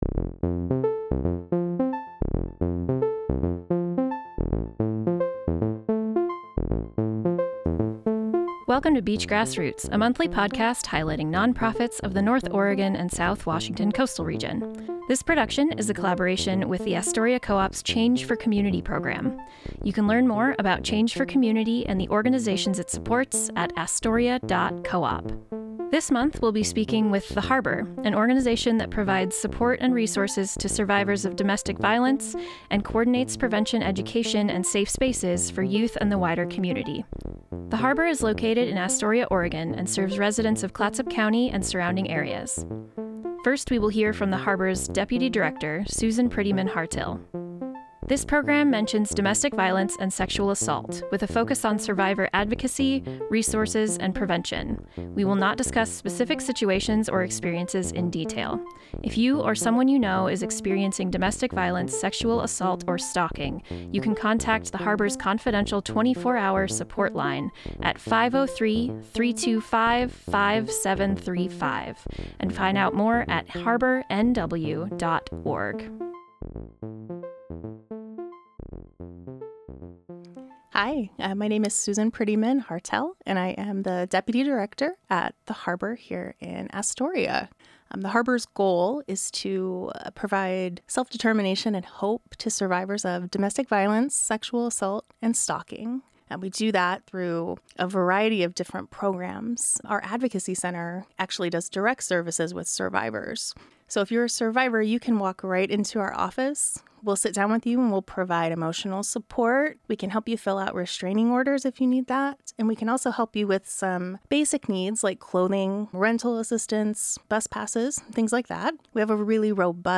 In this episode of Beach Grass Roots, we talk with staff from The Harbor, an organization providing support to survivors of domestic violence in and around the Clatsop County area. The Harbor is the March 2025 beneficiary of the Astoria Co-op’s Change for Community program, which asks customers to round-up their total as a donation to a different local nonprofit each month.